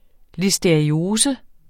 Udtale [ lisdeɐ̯iˈoːsə ]